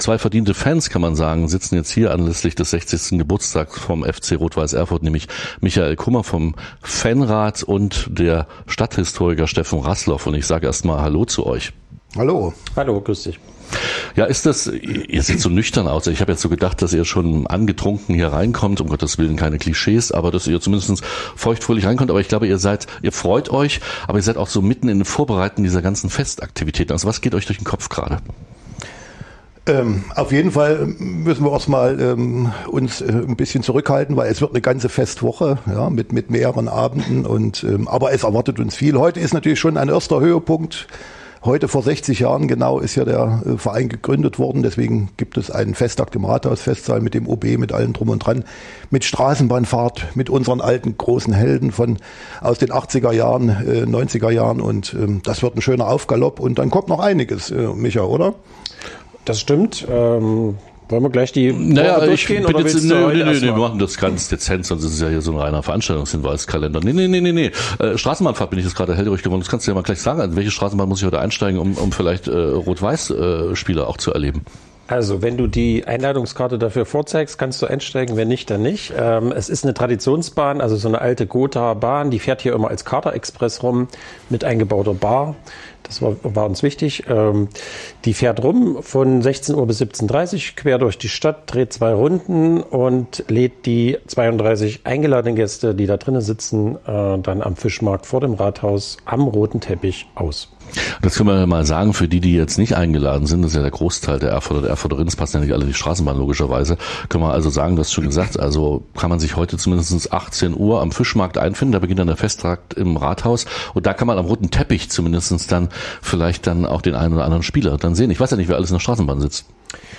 Am Ende der Saison stieg der FC Rot-Weiß Erfurt sogar aus der DDR Oberliga ab. Im Gespräch